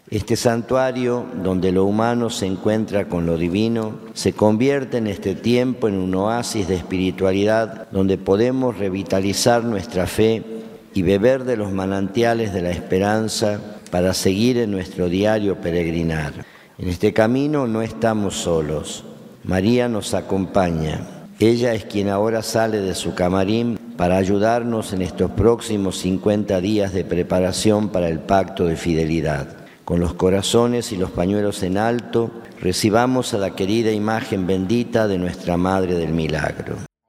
La ceremonia fue presidida por monseñor Mario Cargnello en la Catedral Basílica de Salta.